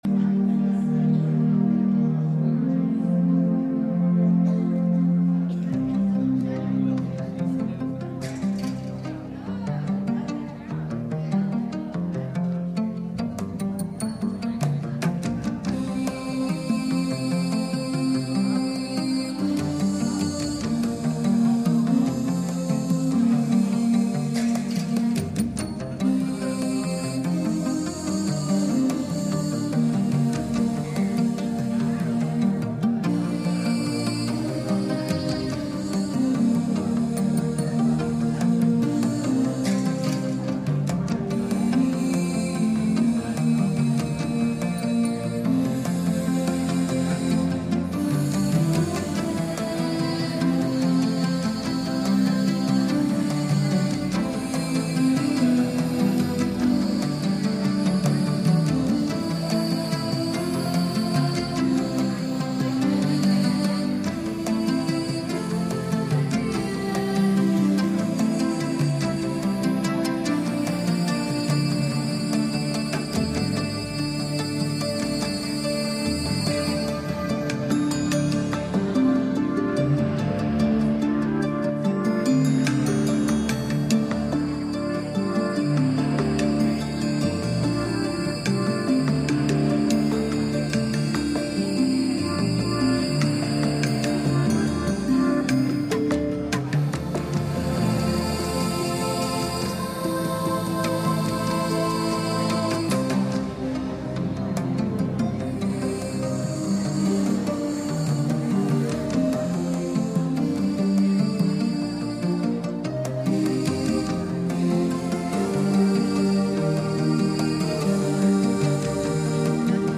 2 Kings 4:1-7 Service Type: Sunday Morning « What’s In Your Hand?